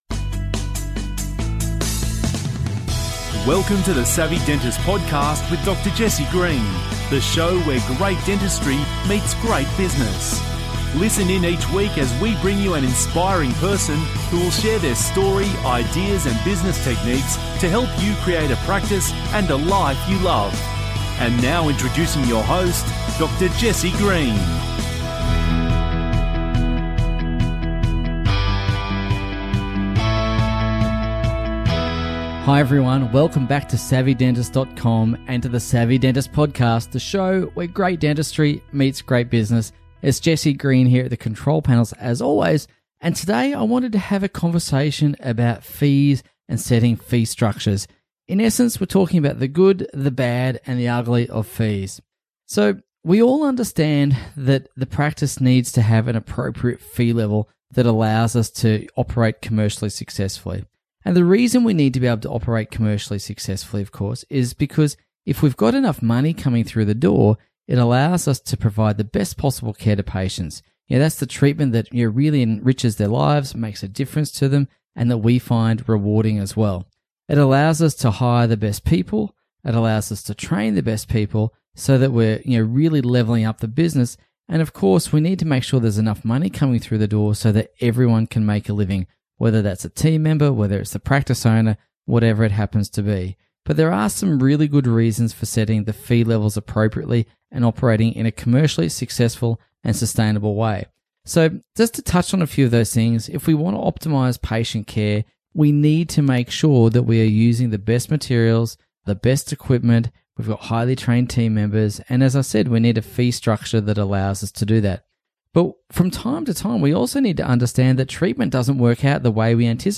We’ve covered quite a lot of ground in this interview such as handling disputes and conflict, getting consent from patients, top things to look out for when buying or selling your practice, common legal mistakes practice owners make and so much more. It was a very thorough and encompassing interview you wouldn’t want to miss.